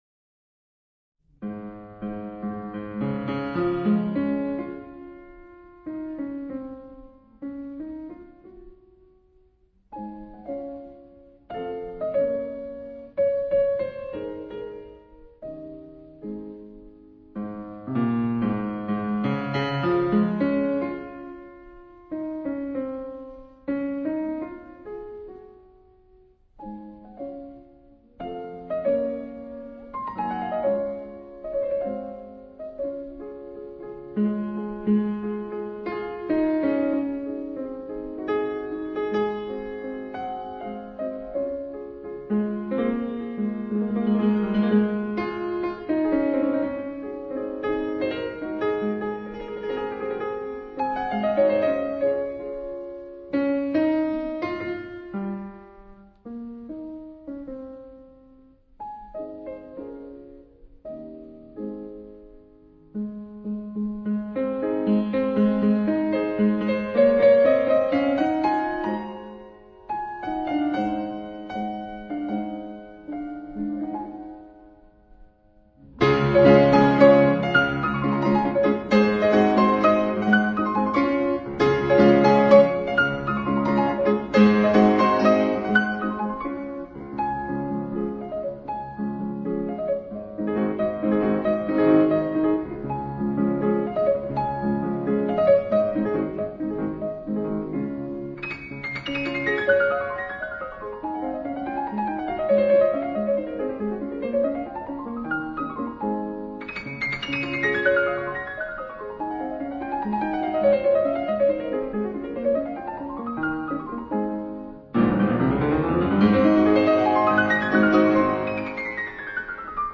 老樂友聽了一段後，說，這味道真不一樣，濃烈又清晰！